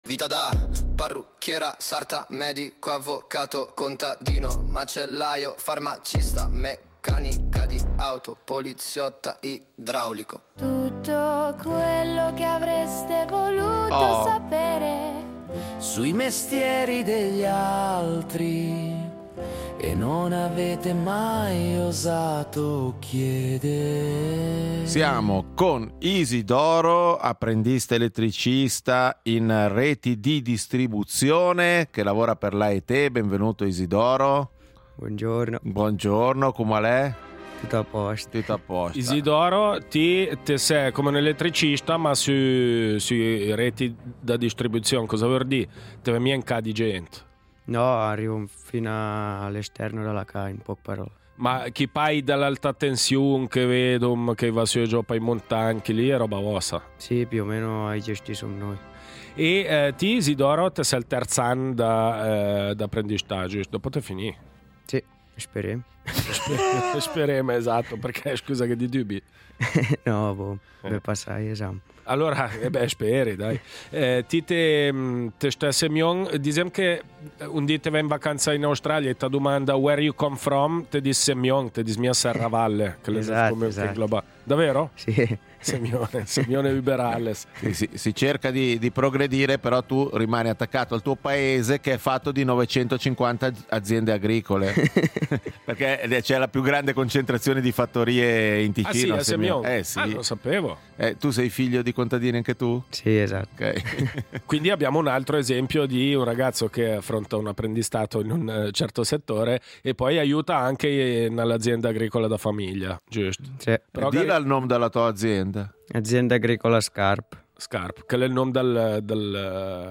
Ospite in studio